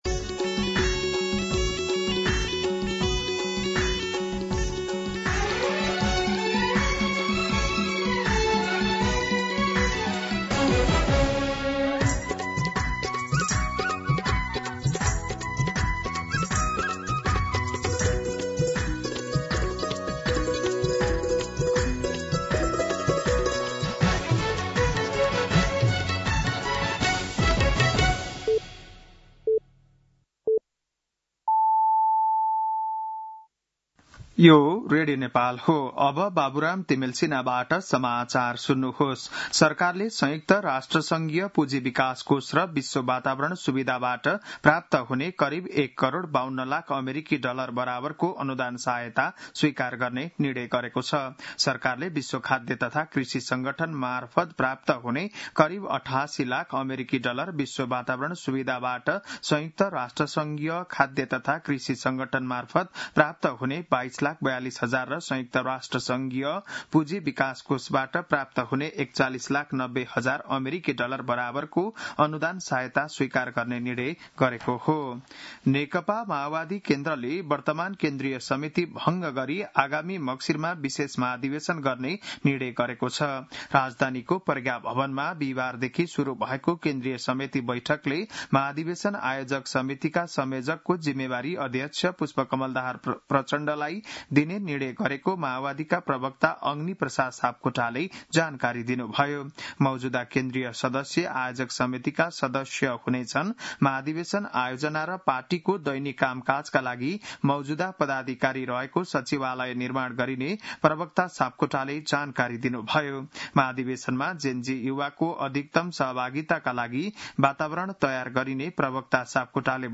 बिहान ११ बजेको नेपाली समाचार : १८ पुष , २०२६
11-am-Nepali-News-4.mp3